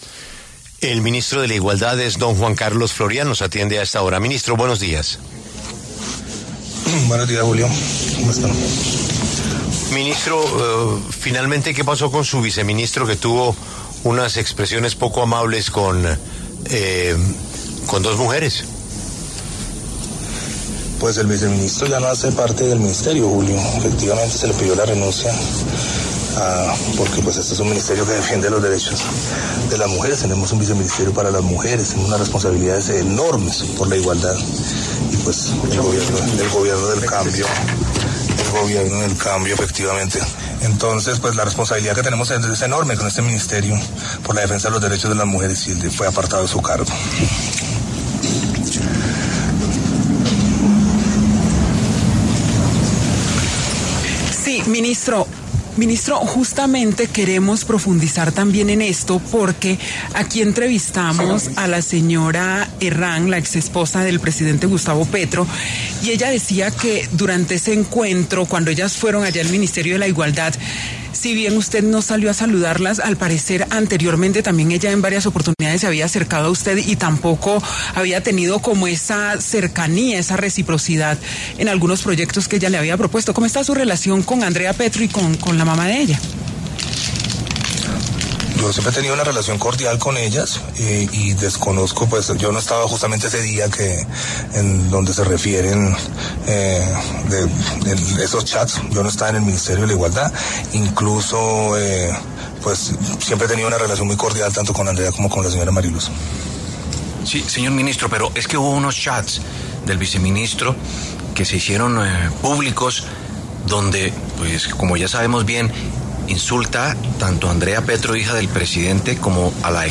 El ministro de Igualdad, Juan Carlos Florián, conversó con La W sobre la reciente controversia en torno al ex viceministro de Igualdad, Dumar Guevara.